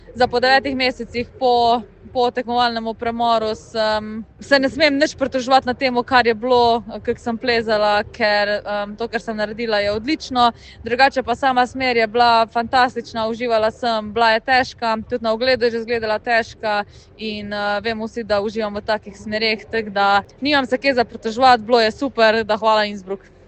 izjava Janja Garnbret